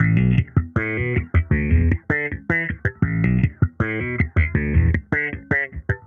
Index of /musicradar/sampled-funk-soul-samples/79bpm/Bass
SSF_JBassProc1_79B.wav